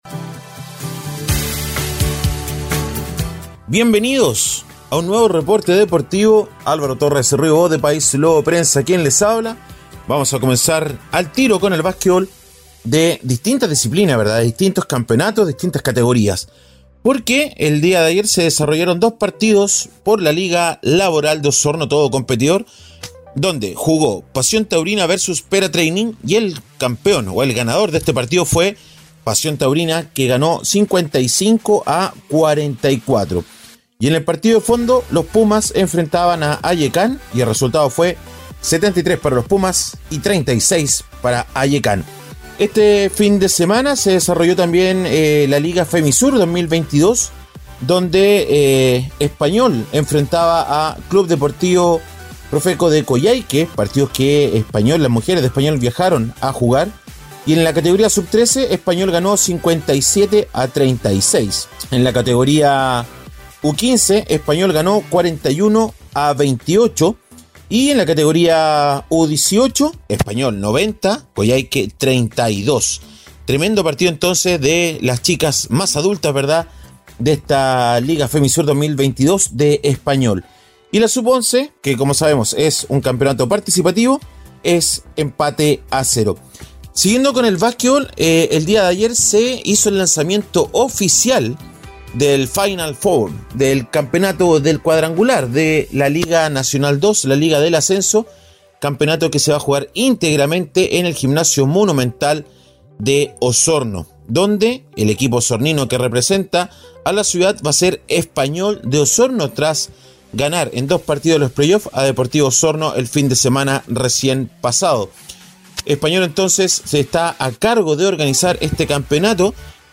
Reporte Deportivo 🎙 Podcast 18 de agosto de 2022